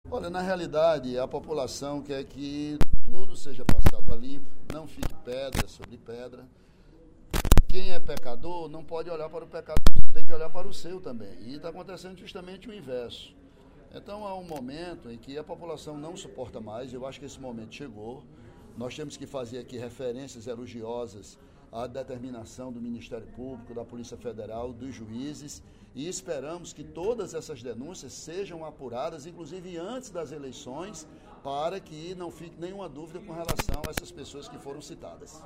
O deputado Ely Aguiar (PSDC) defendeu, nesta terça-feira (23/05), durante o primeiro expediente da sessão plenária, que todas as denúncias de pagamento de propina – feitas pelos donos e diretores da empresa JBS em delação premiada – sejam investigadas.